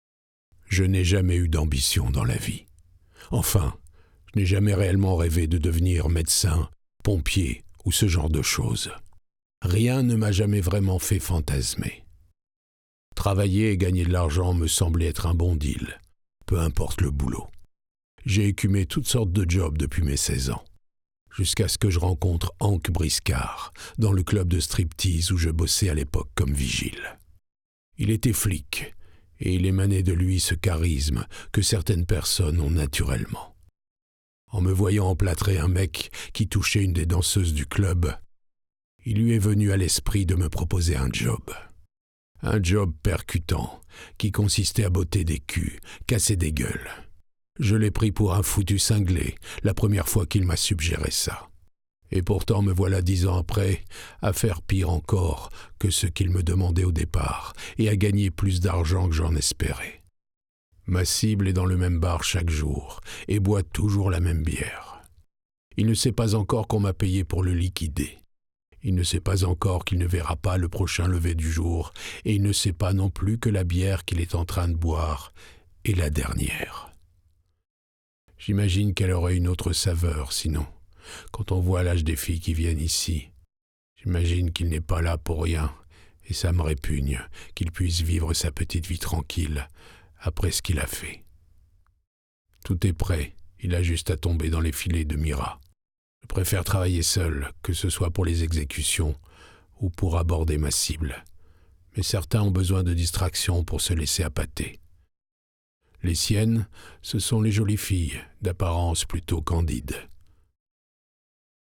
LIVRE AUDIO POLAR
30 - 45 ans - Baryton Ténor